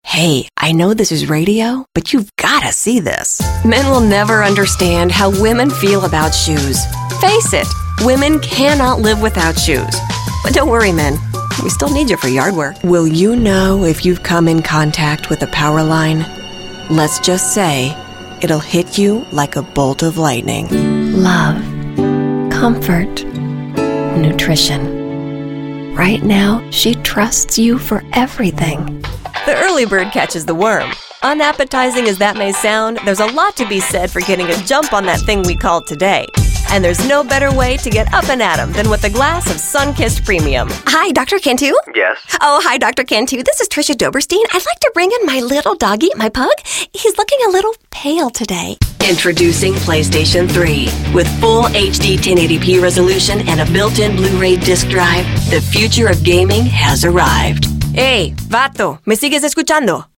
middle west
Sprechprobe: Werbung (Muttersprache):
Professional female voiceover specializing in corporate and technical narration.